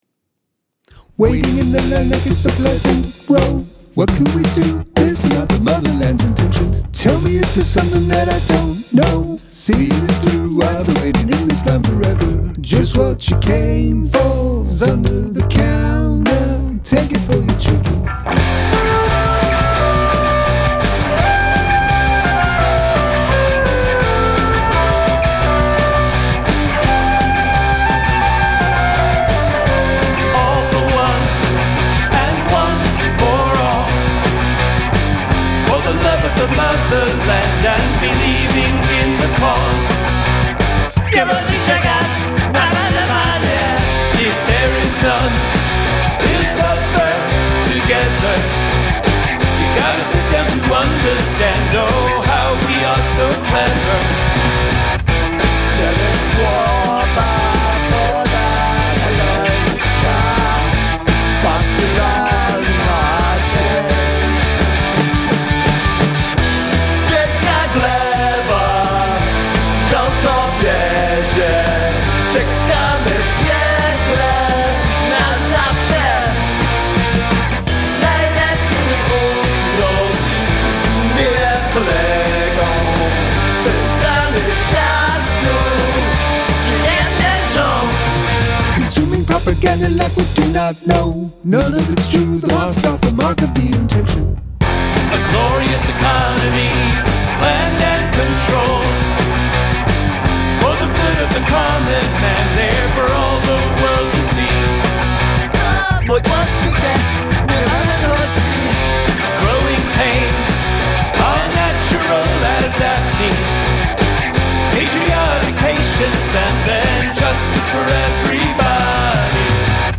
Must include a guest singing or speaking in another language
Interesting rhythm/melody combo on the intro.
The doubling (octave?) is a nice touch.